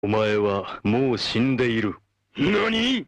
Play omaewa, Download and Share now on SoundBoardGuy!